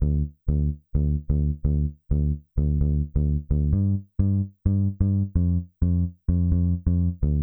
BAL Bass Riff D-G-F.wav